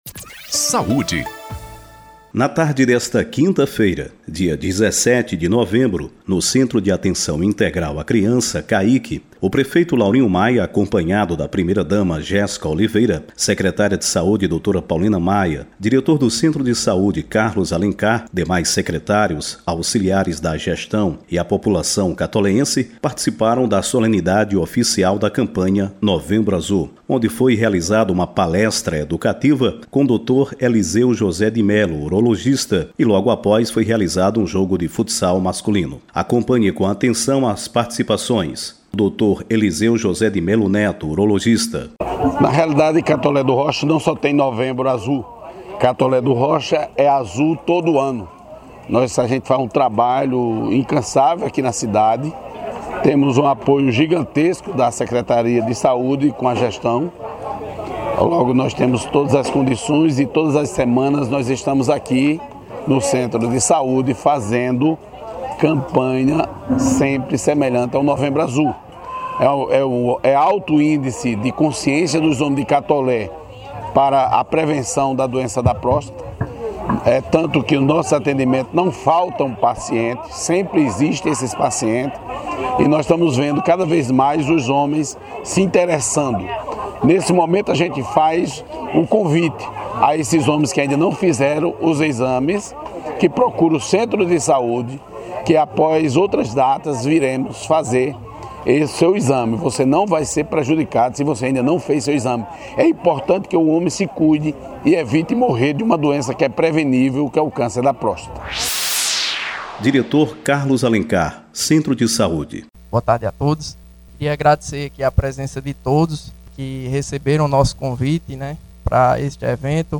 A Prefeitura de Catolé do Rocha – Secretaria Municipal de Saúde – realizou, na tarde de quinta-feira (17/11), a Solenidade Oficial da Campanha “Novembro Azul”.
O evento aconteceu na UBS do CAIC (Centro de Atenção Integral à Criança) e contou com palestra educativa sobre o Câncer de Próstata e a realização de Jogo de Futsal Masculino.